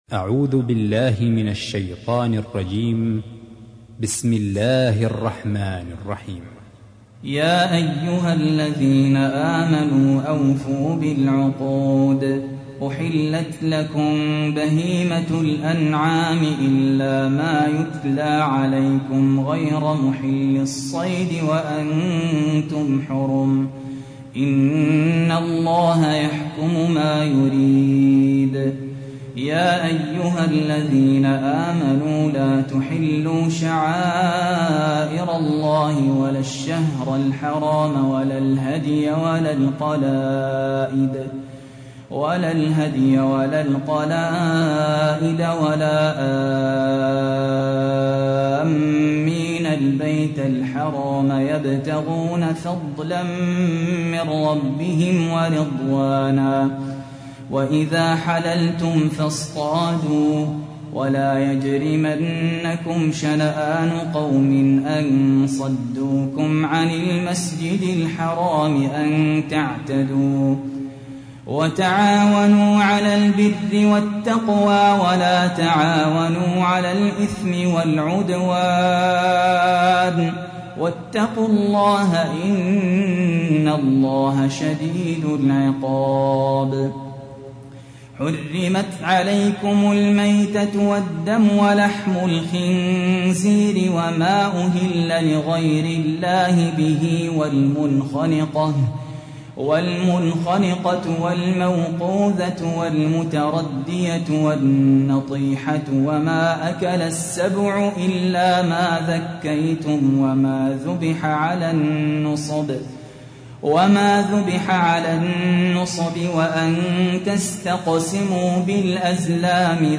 تحميل : 5. سورة المائدة / القارئ سهل ياسين / القرآن الكريم / موقع يا حسين